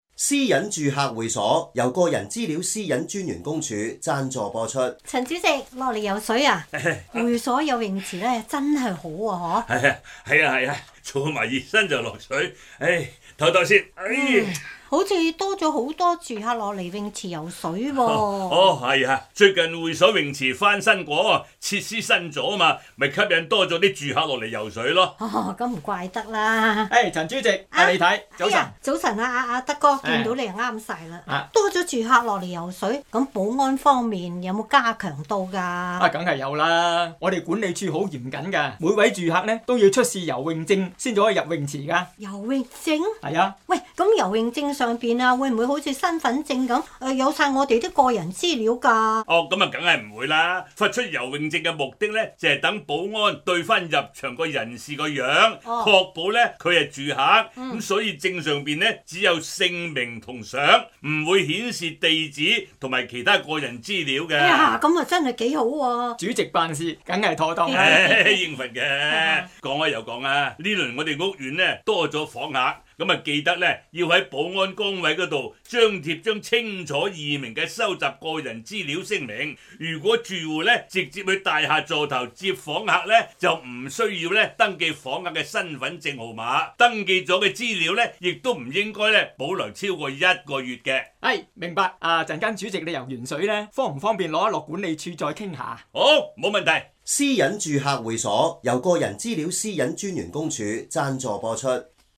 Radio Drama Series